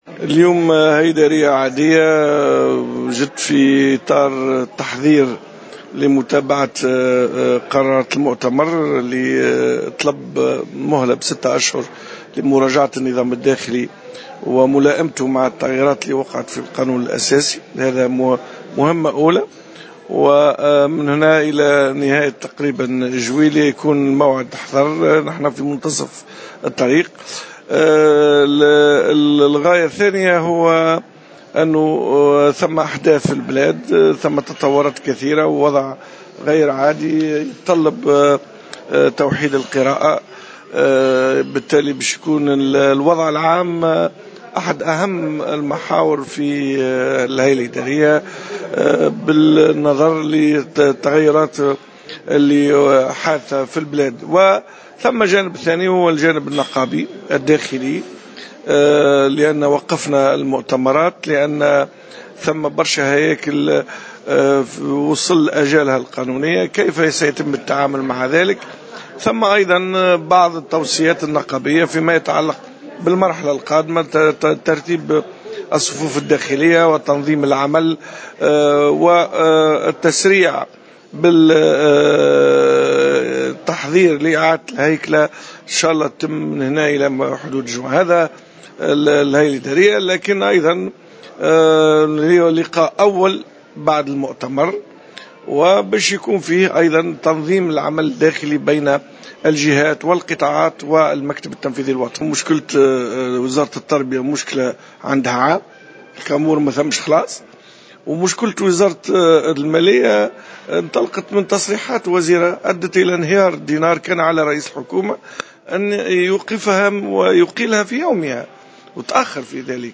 En marge d’une réunion du comité administratif de la centrale ouvrière à Hammamet